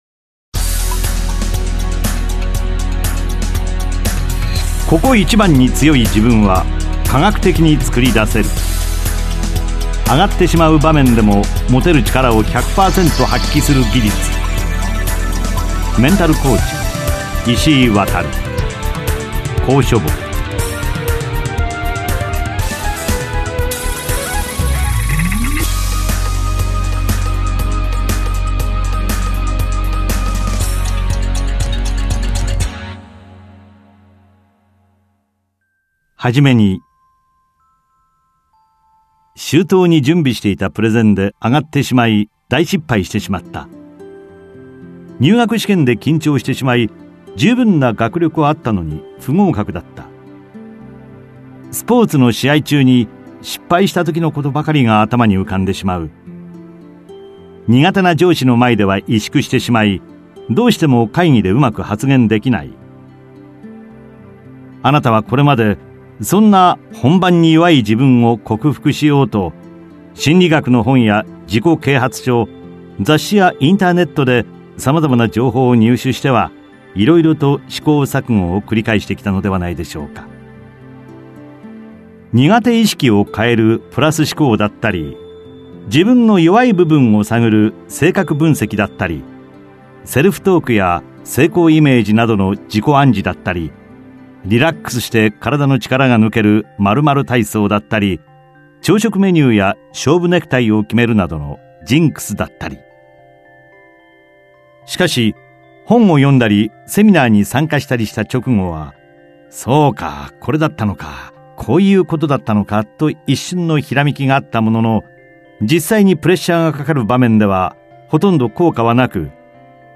[オーディオブック] 「ここ一番に強い自分」は科学的に作り出せる